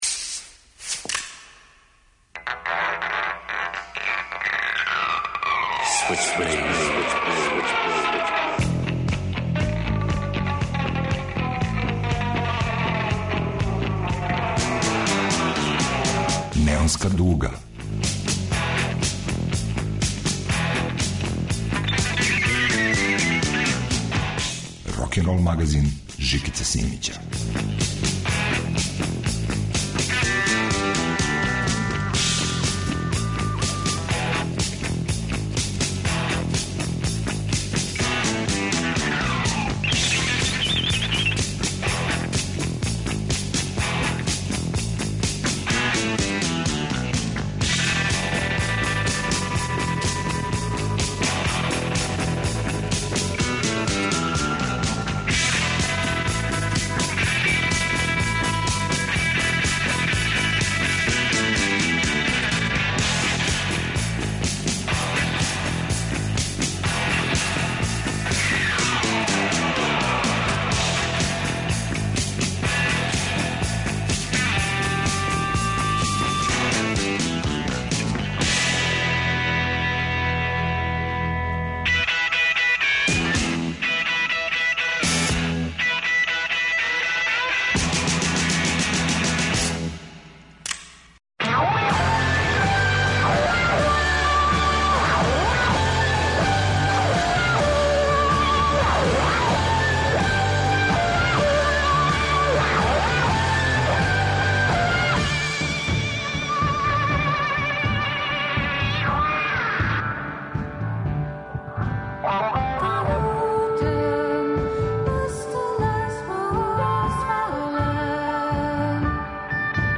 Рокенрол као музички скор за живот на дивљој страни. Вратоломни сурф кроз време и жанрове. Старо и ново у нераскидивом загрљају.